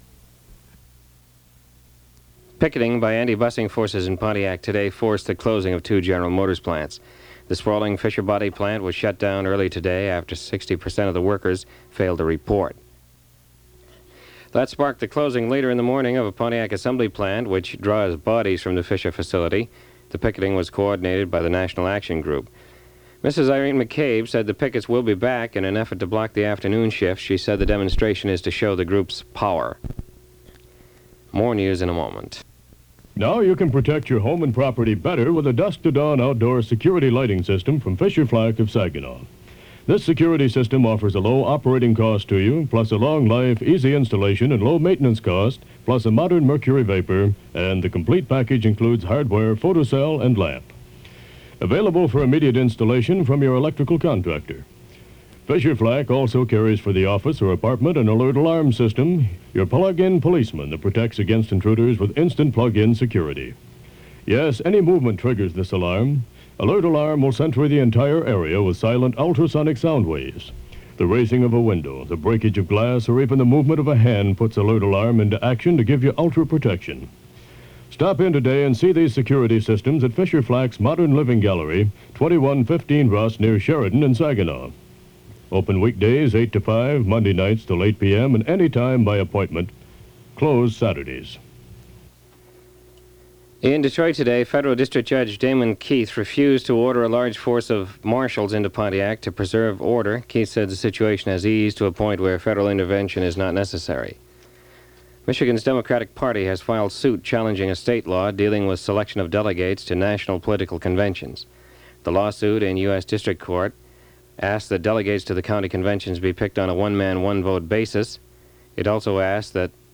Here are some neat recordings I was able to save from the early 1970's.   These are local radio stations around the thumb area Michigan WGER and WGMZ.